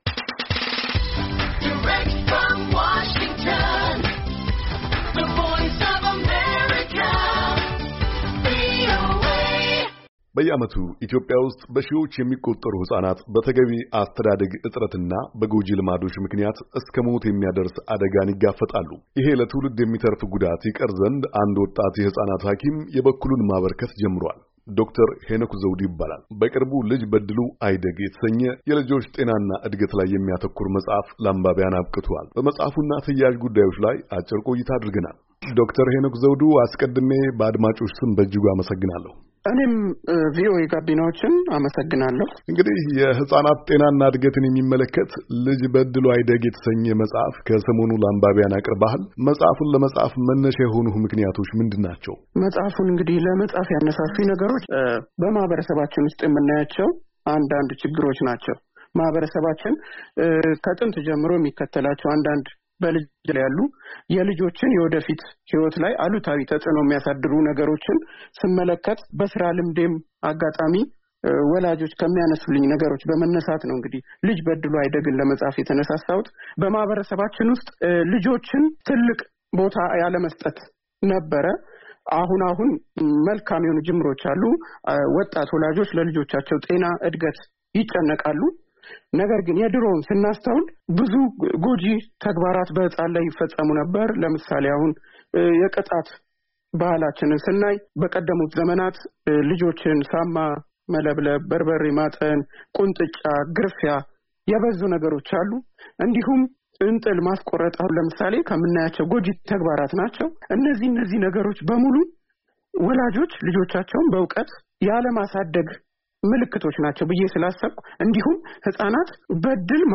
በመጽሃፉ እና ተያኣዥ ጉዳዮች ላይ አጭር ቆይታ አድርገናል።